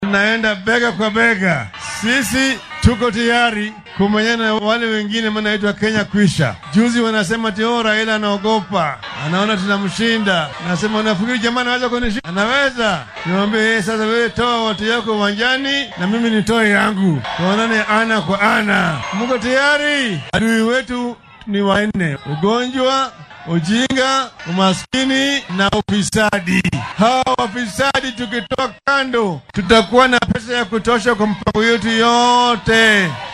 Raila Oding ayaa xilli uu dadweynaha kula hadlay xaafadda Makongeni xusay inay si wayn ula dagaallami doonaan musuqmaasuqa si loo helo lacago lagu fulin karo ballanqaadyada horumarineed ee isbeheysiga Azimio.